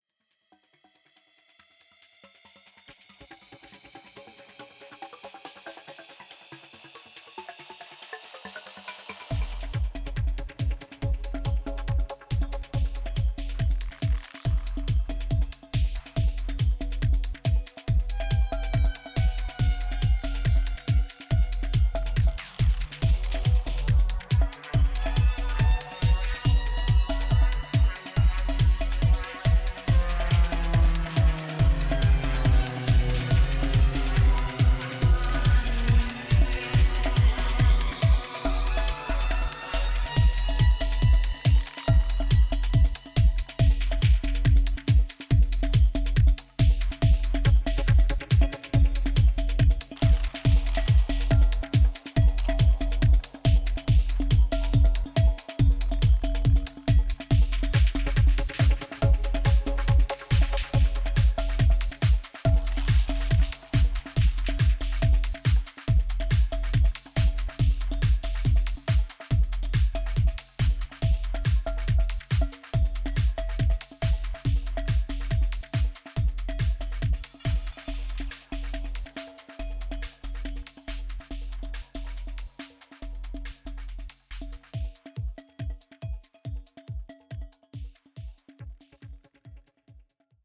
ソリッドに刻むステッパーズなキックとダビーなパーカッションアレンジに飛ばされる
オーガニックなテクスチャーを軸に卓越したグルーヴ感とサイケデリックな音響志向が見事に合致したテクノの新感覚を提示。